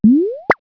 bublup0r.wav